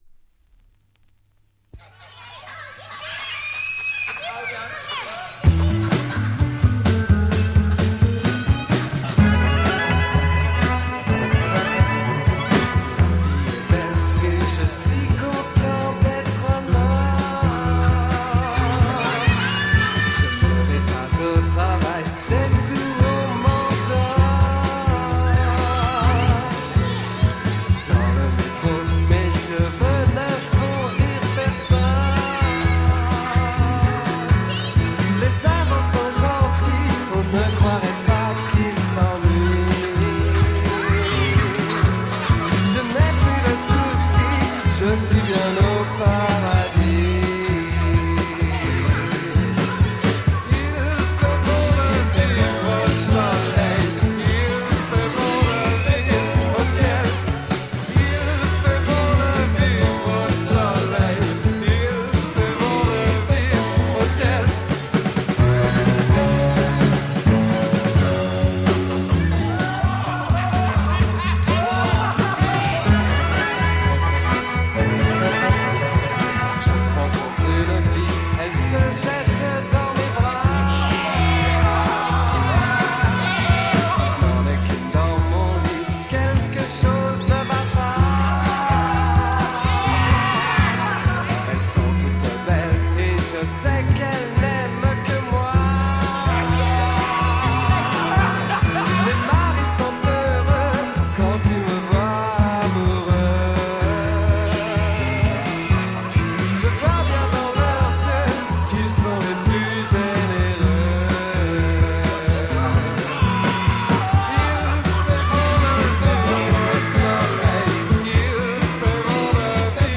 Killer French Fuzz-rock Psych
Probalbly the best French heavy Prog-rock single !